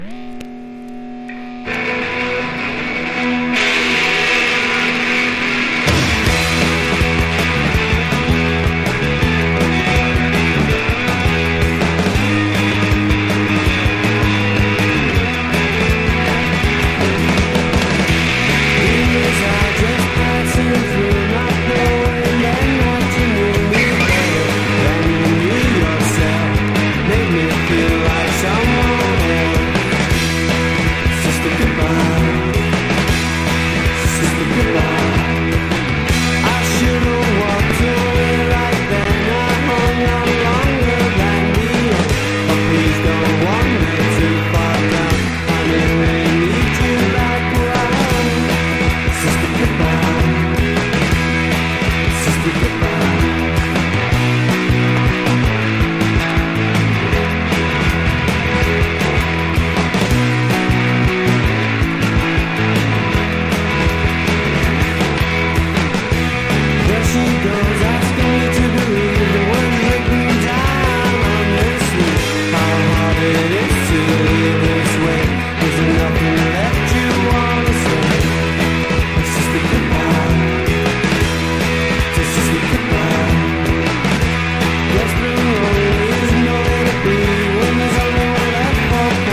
とにかくメロがイイです。
NEO ACOUSTIC / GUITAR POP